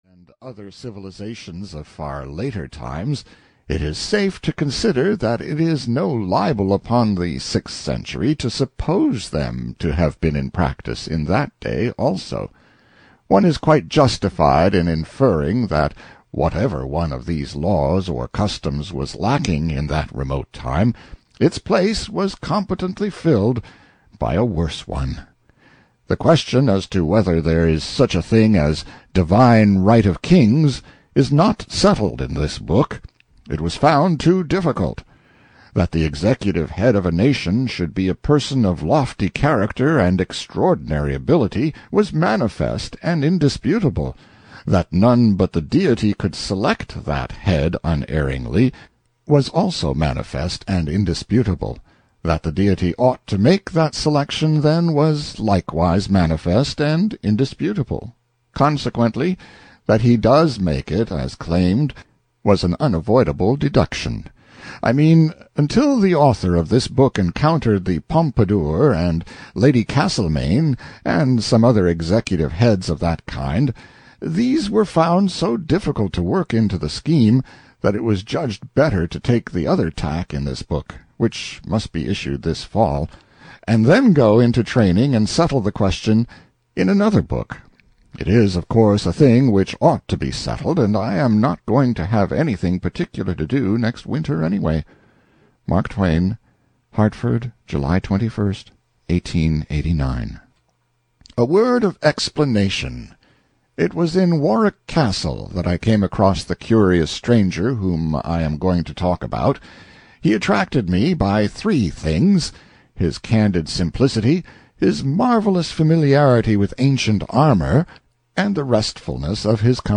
A Connecticut Yankee at the Court of King Arthur (EN) audiokniha
Ukázka z knihy